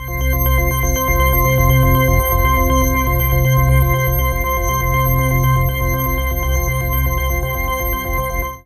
36ai01pad-cM.wav